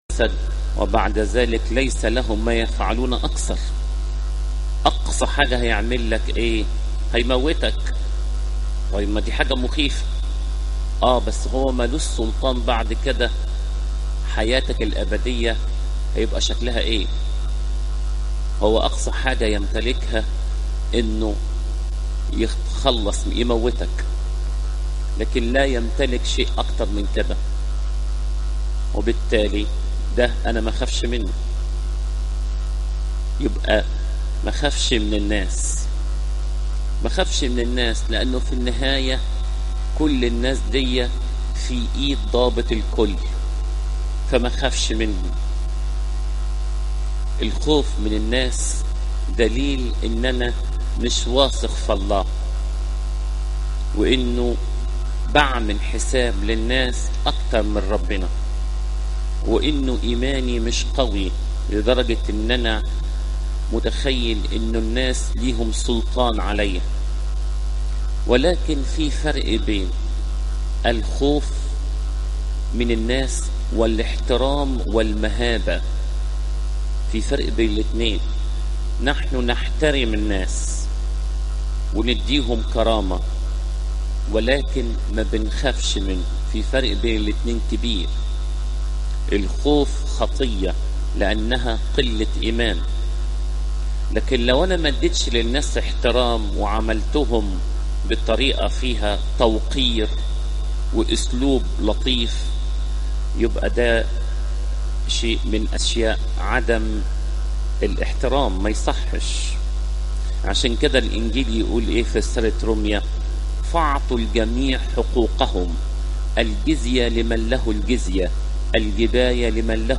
عظات قداسات الكنيسة صوم العذراء مريم (لو 11 : 53 - 12 : 12)